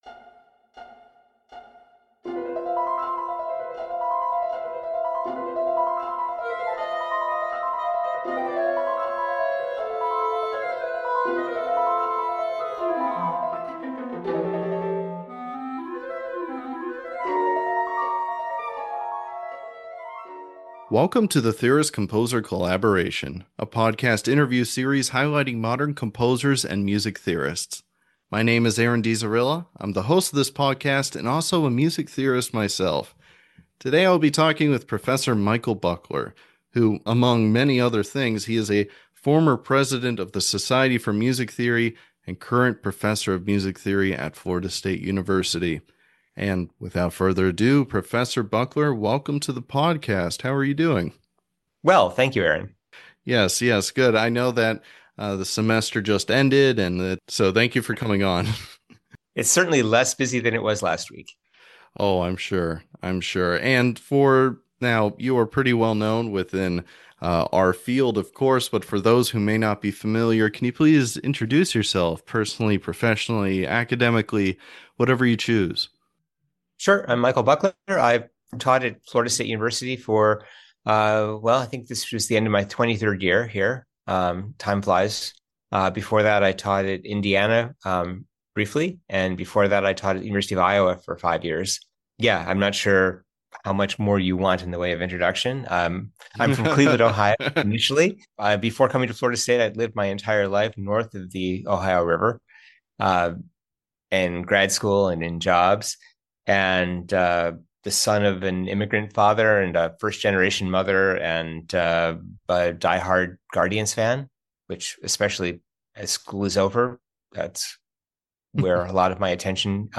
The Land of Cloud-Tinted Water was performed by the Minnesota Percussion Trio